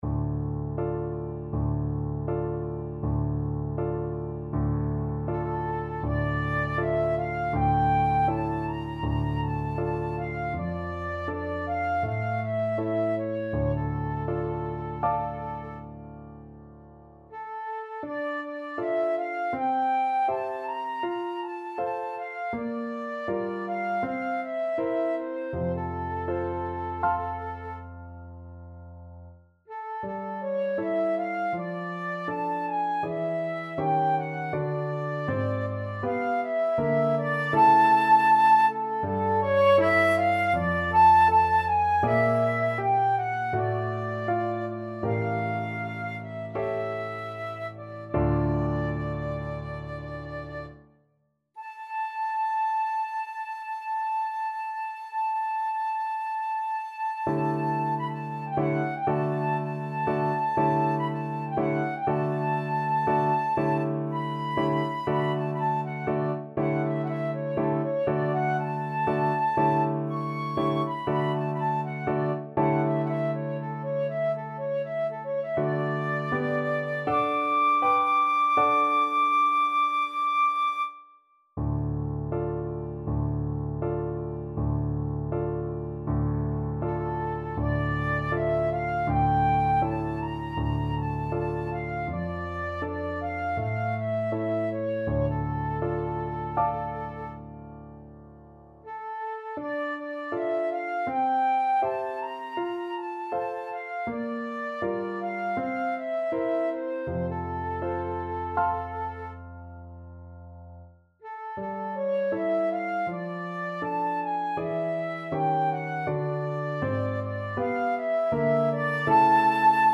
4/4 (View more 4/4 Music)
Un poco andante
Classical (View more Classical Flute Music)
piano and flute not synced